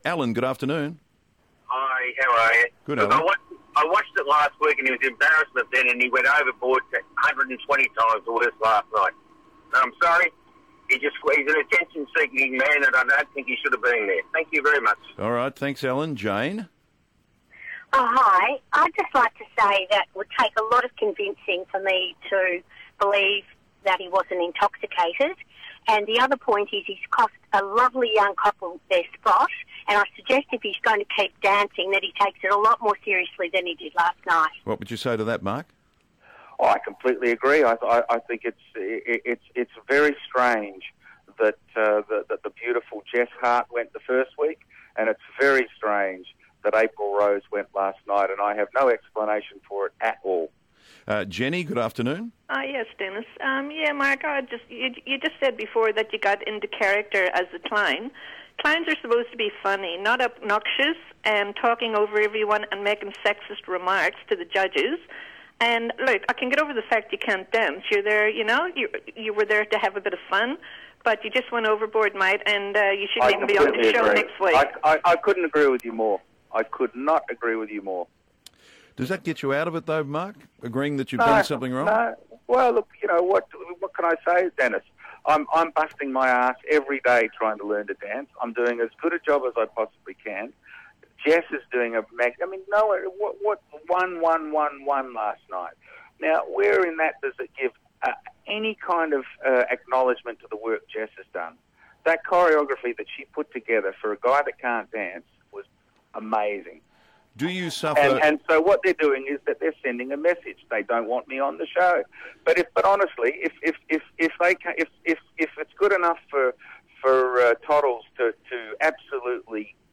Mark Holden cops a barrage of talk back callers
Angry views ring in with their thoughts on Mark Holden's performance.